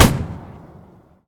mg-shot-7.ogg